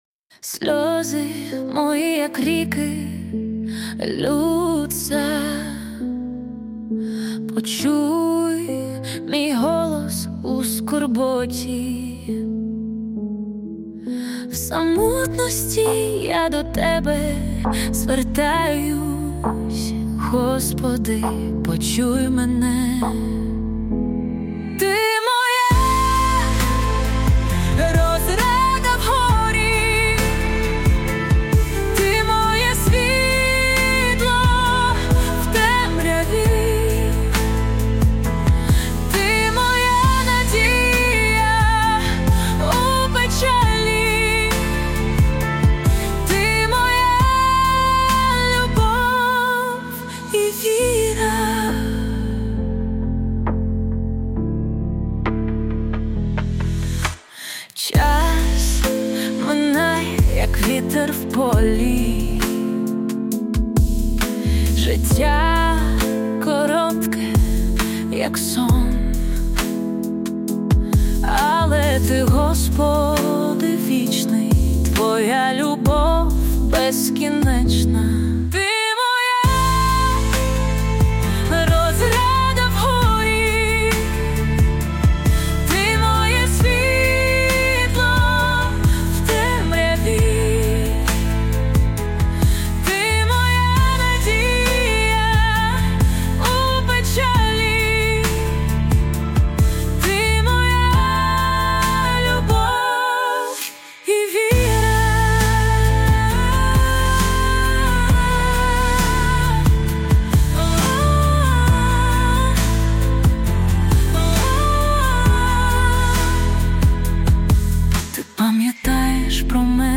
песня ai